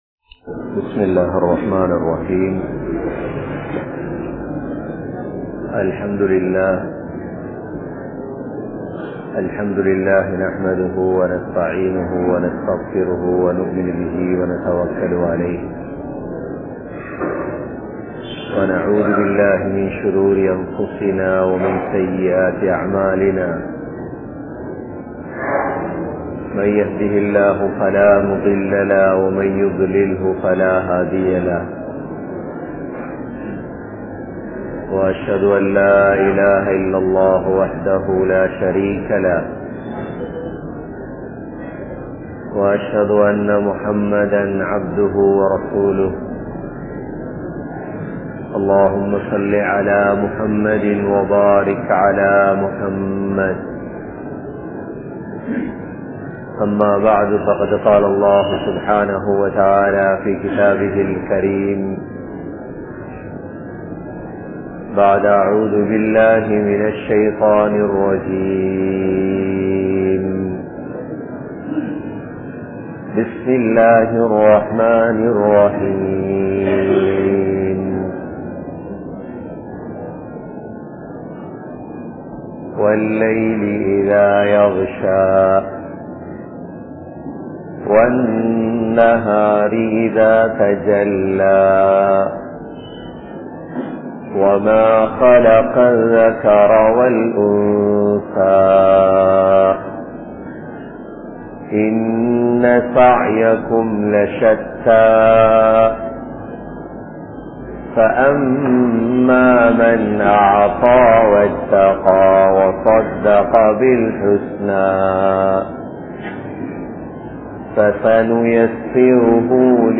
Allah`vukkaaha Vaalungal (அல்லாஹ்வுக்காக வாழுங்கள்) | Audio Bayans | All Ceylon Muslim Youth Community | Addalaichenai